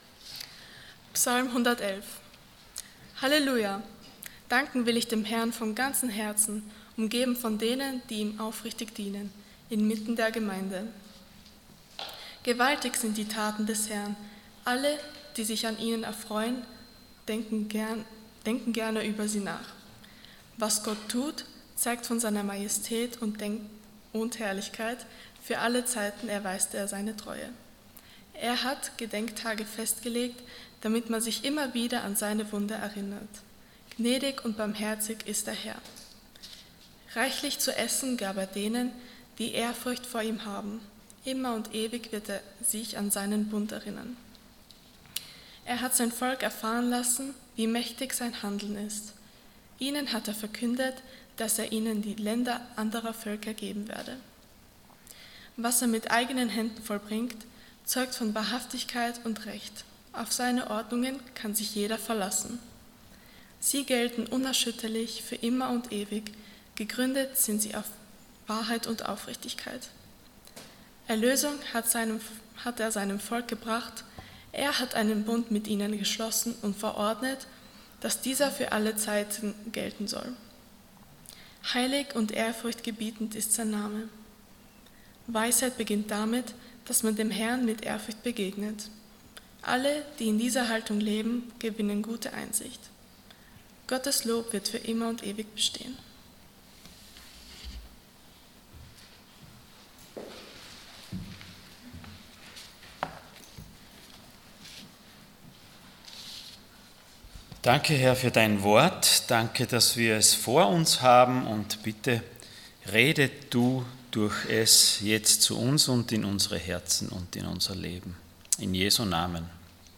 Allgemeine Predigten Passage: Psalm 111 Dienstart: Sonntag Morgen Lebst du noch oder lobst du schon?